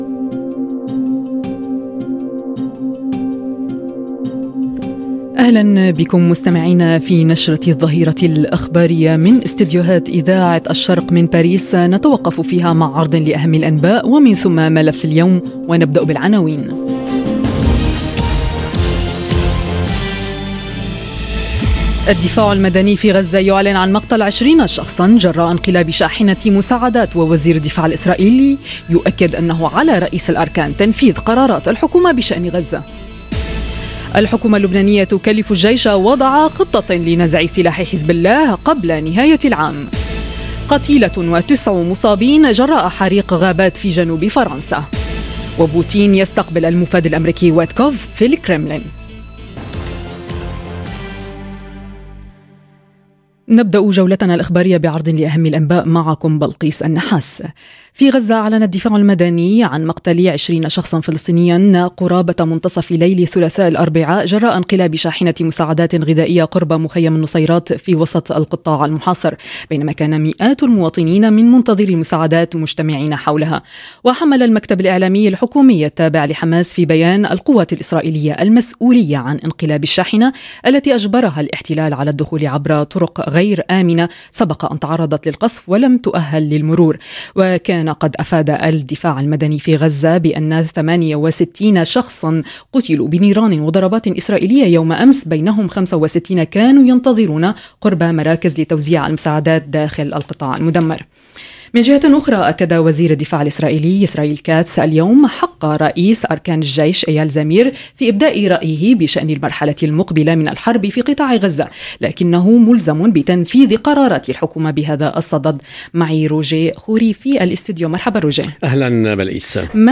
نشرة أخبار الظهيرة: الدفاع المدني في غزة يعلن مقتل 20 شخصا جراء انقلاب شاحنة مساعدات والحكومة اللبنانية تكلف الجيش بوضع خطة لنزع سلاح حزب الله - Radio ORIENT، إذاعة الشرق من باريس